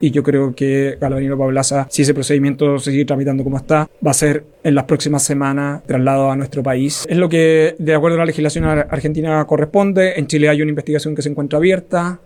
Desde el Gobierno, el ministro de Seguridad Pública, Luis Cordero, señaló que, si el procedimiento sigue su curso como hasta ahora, Apablaza será trasladado al país “en las próximas semanas”, recordando que en Chile hay una investigación abierta en su contra.